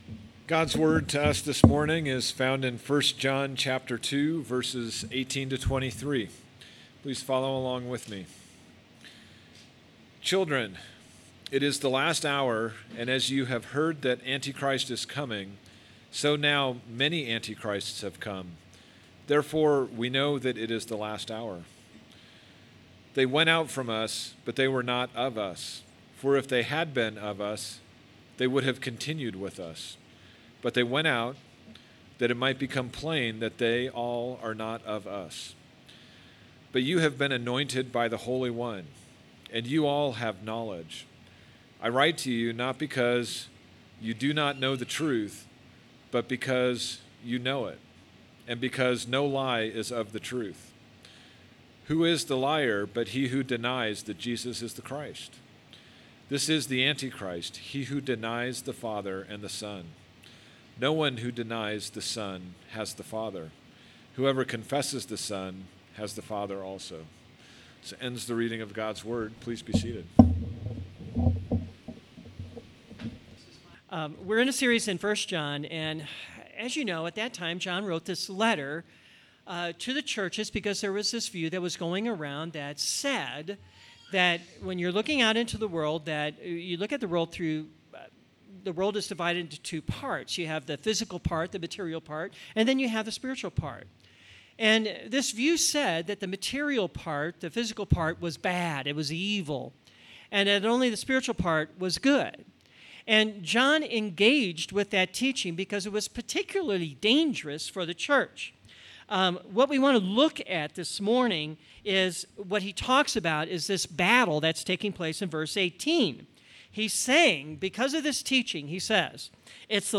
Sermons - Redeemer Presbyterian Church